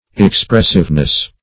expressiveness \ex*press"ive*ness\ n.